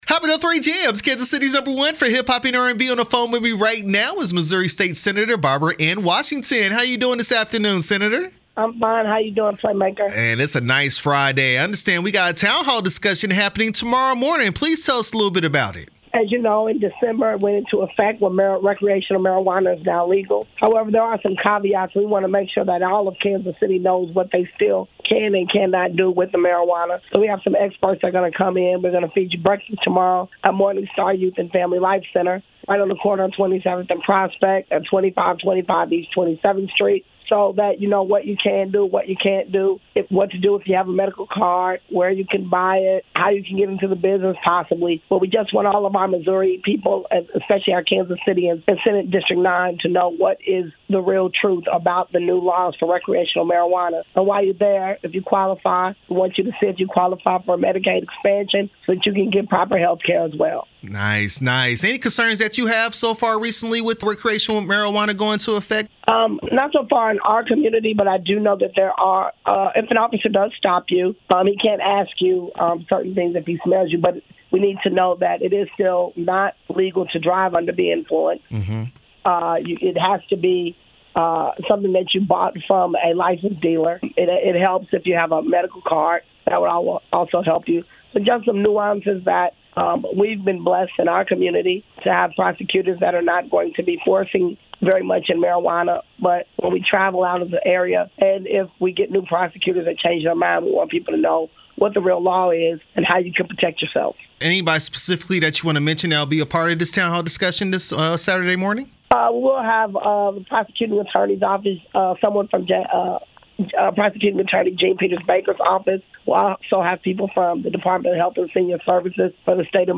State Senator Barbara Anne Washington Town Hall interview 2/17/23